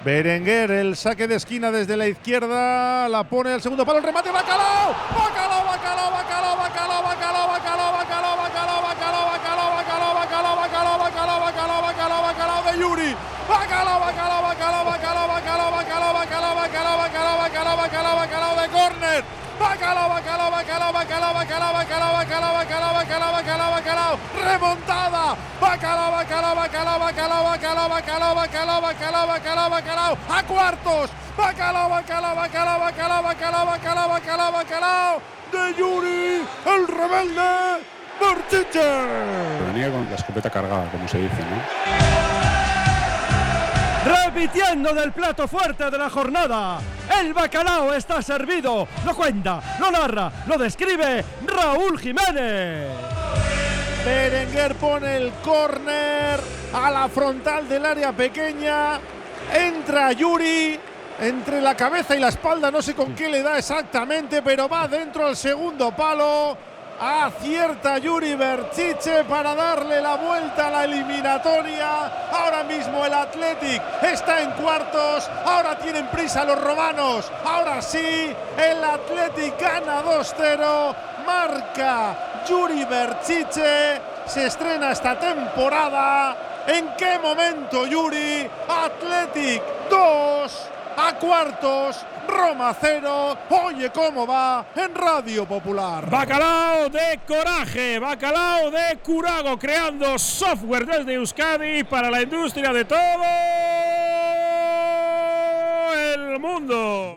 Yuri Berchiche marca el bacalao que adelantaba al Athletic en la eliminatoria en San Mamés ante la Roma.